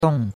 dong4.mp3